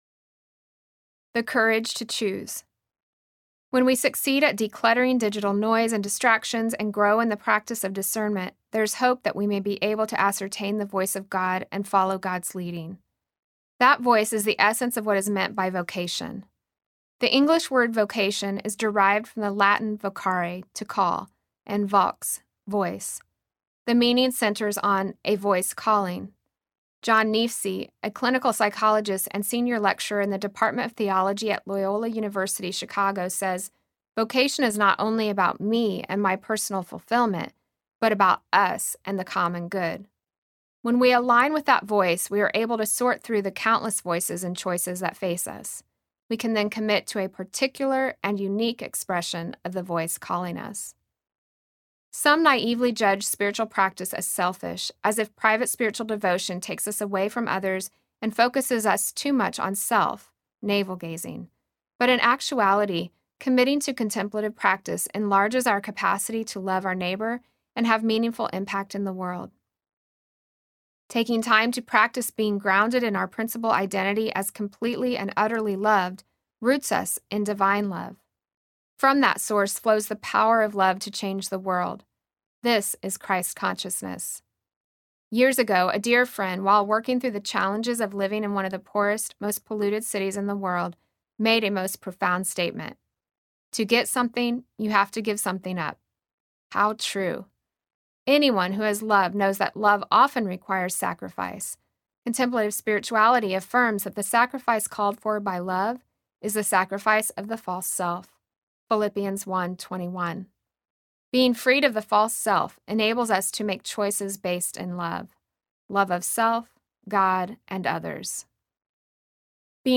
Mindful Silence Audiobook
5.60 Hrs. – Unabridged